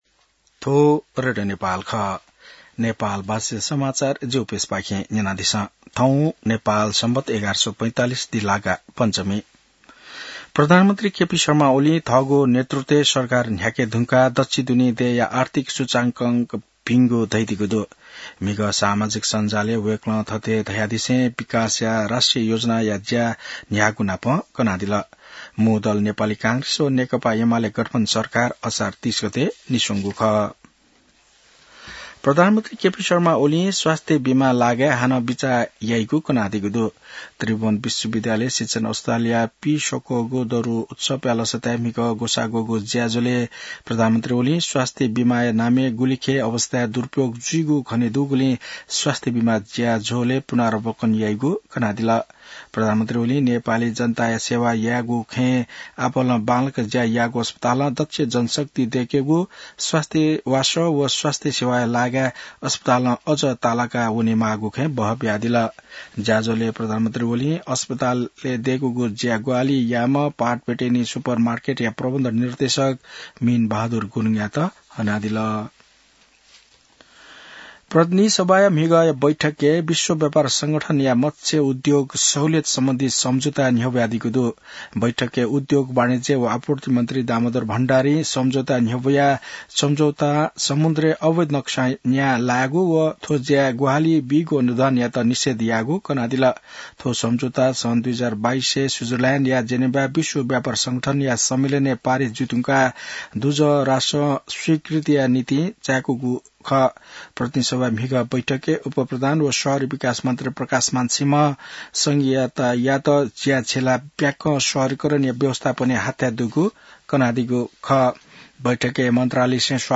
नेपाल भाषामा समाचार : ३१ असार , २०८२